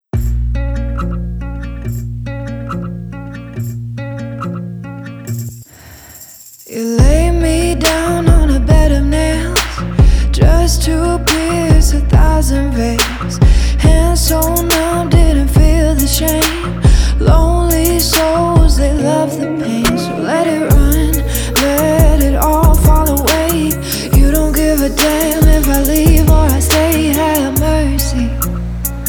• Качество: 320, Stereo
чувственные
indie pop
Bass
alternative
Чувственная музыка